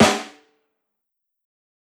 SNARE_GULY.wav